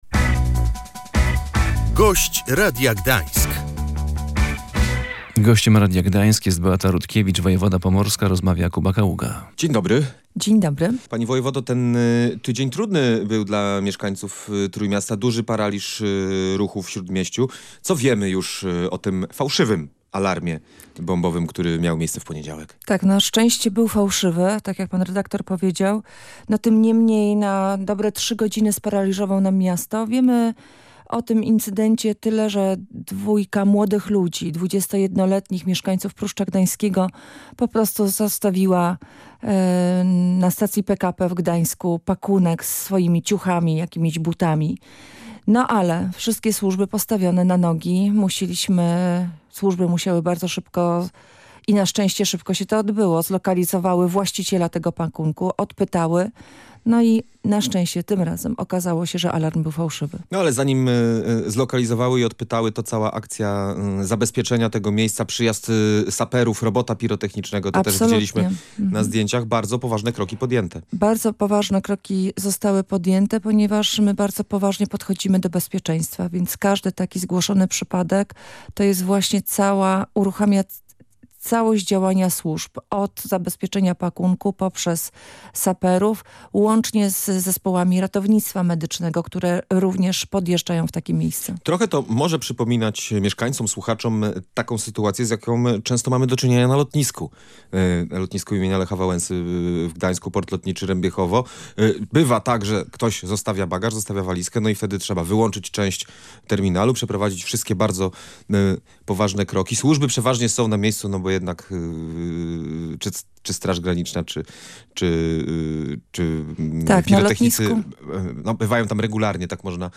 Do niepokojących akcji i alarmów musimy się przyzwyczaić – mówiła w Radiu Gdańsk Beata Rutkiewicz, wojewoda pomorska. W poniedziałek dwójka młodych osób zostawiła bez opieki torbę na dworcu PKP w Gdańsku Głównym.
Gość Radia Gdańsk